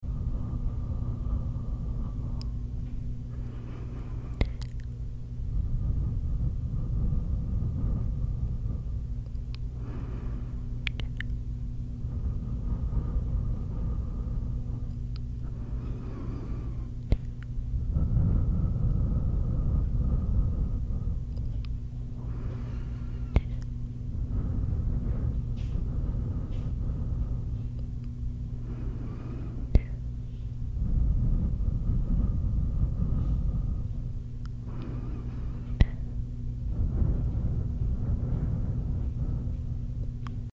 Asmr Blowing Wind In Your Sound Effects Free Download